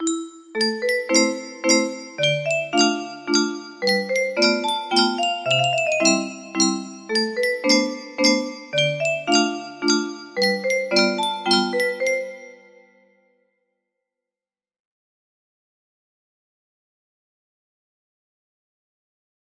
Waltz in something music box melody